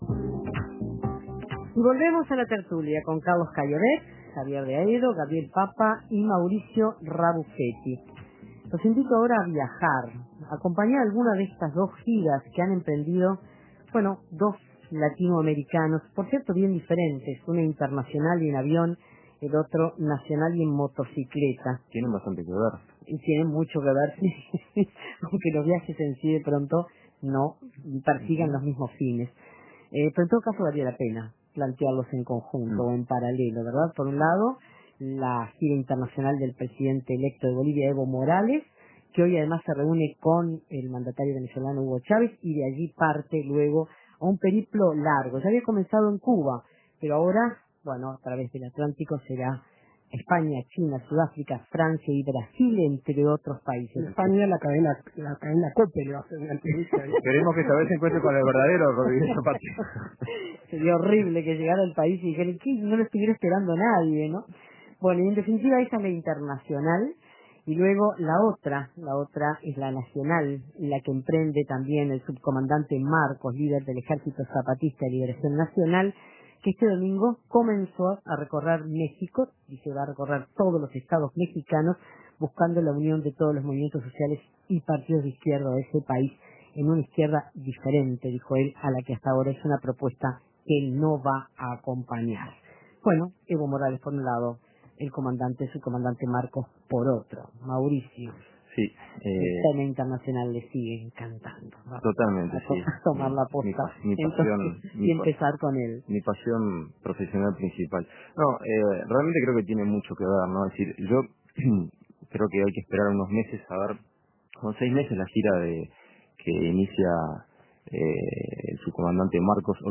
Los contertulios miran el continente.
La Tertulia Evo Morales y el subcomandante Marcos, cada uno por su lado, emprenden una gira en estos días.